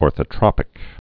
(ôrthə-trŏpĭk, -trōpĭk)